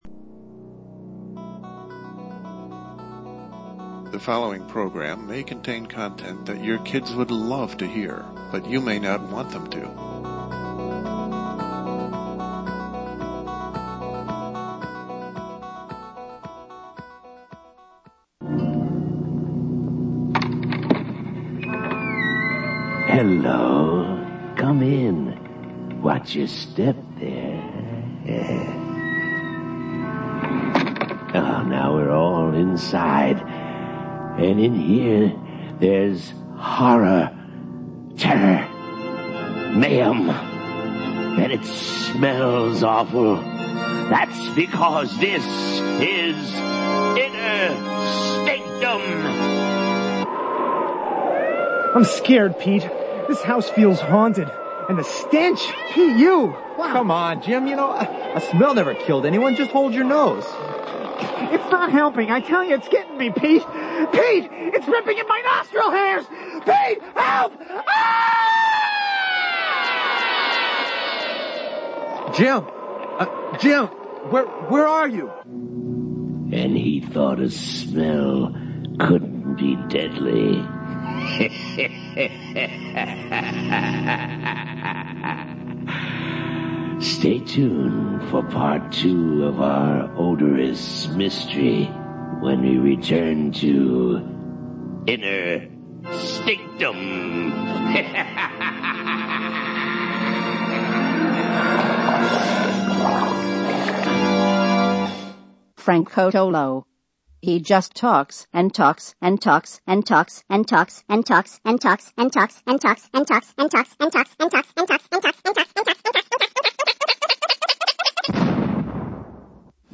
The Polar Vortex is gone for now, so on we go with live broadcasts after a chilling January. A number of topics are for the offering, including the Flex Tape Twitter Attack, discount drones, 2019 movie trends and the recent prediction that the world may run out of people.